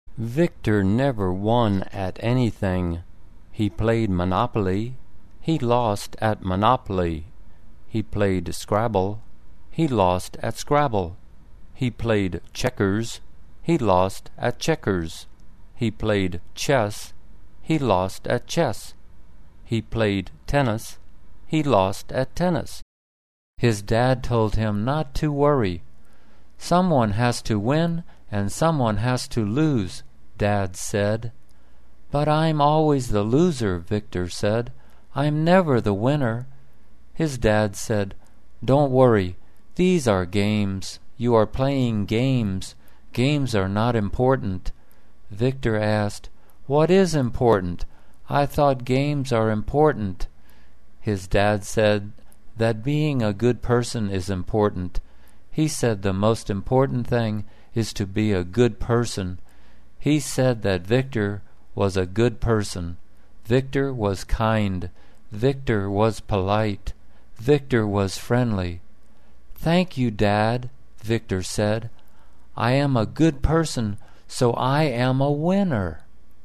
简单慢速英语阅读:The Winner 听力文件下载—在线英语听力室